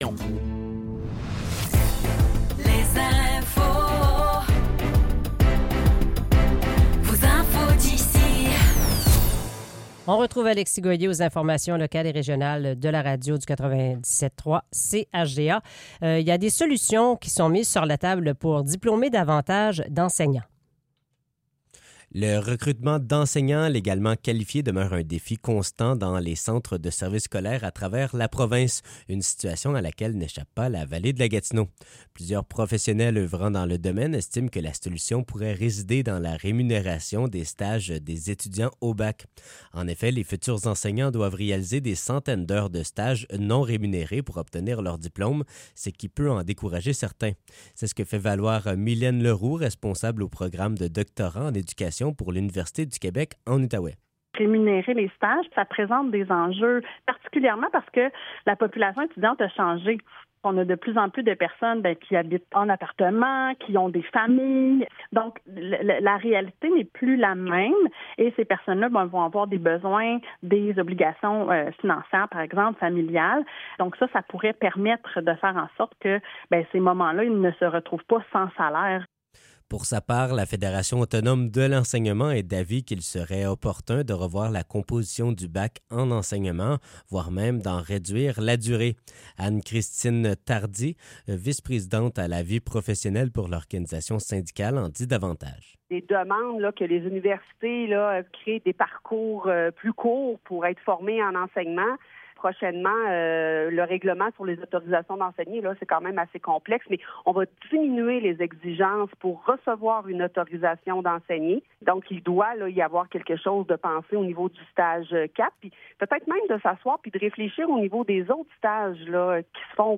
Nouvelles locales - 18 octobre 2024 - 7 h